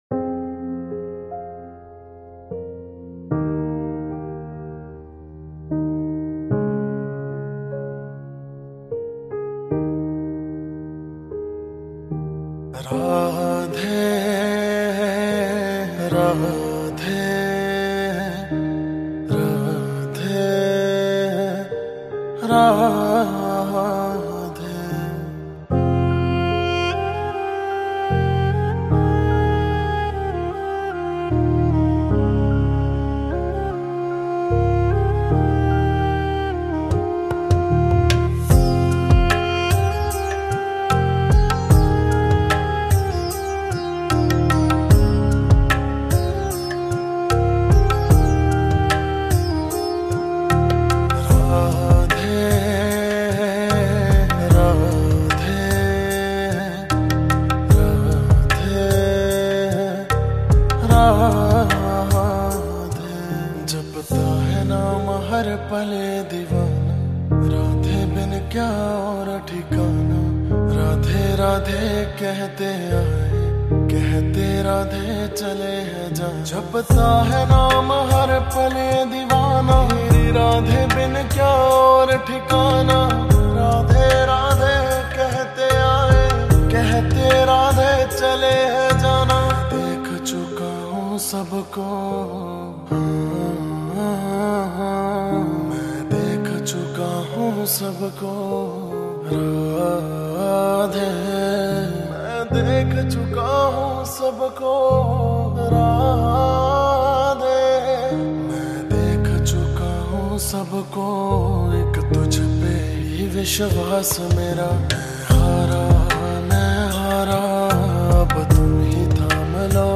This song is a devotional track filled with devotion.